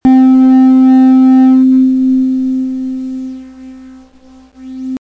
Tuning fork 2
Category: Sound FX   Right: Personal